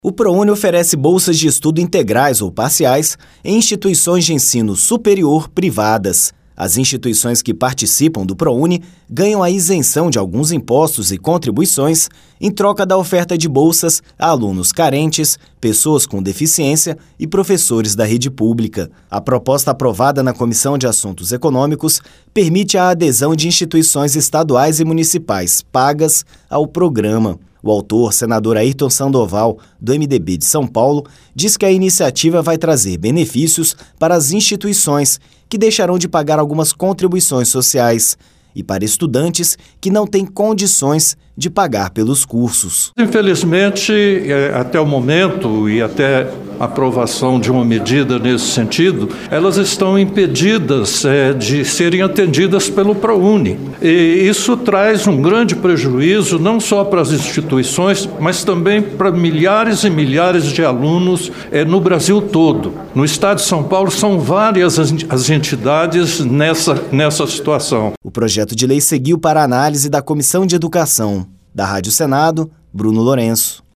O autor, senador Airton Sandoval (MDB-SP), diz que a iniciativa vai trazer benefícios para as instituições, que deixarão de pagar algumas contribuições sociais, e para estudantes, que não têm condições de pagar pelos cursos. A reportagem